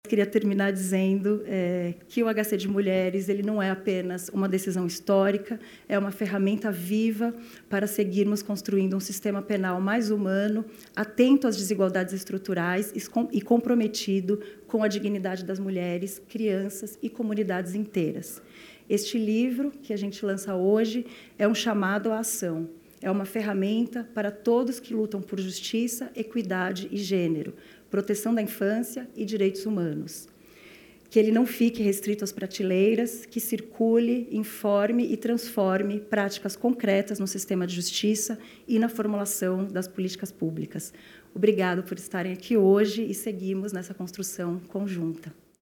Marta Machado, Secretária Nacional de Políticas sobre Drogas, comenta o papel do HC no trato às mulheres encarceradas — Ministério da Justiça e Segurança Pública